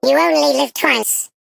Sfx_tool_spypenguin_vo_rebuilt_02.ogg